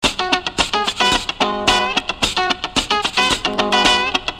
吉他和打击乐演奏110
描述：吉他和打击乐器
Tag: 110 bpm Funk Loops Guitar Electric Loops 755.71 KB wav Key : Unknown